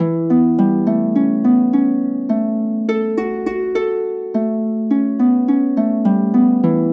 без эквализации у нее как по мне средина гудид немного район 250-500 Hz
harp.mp3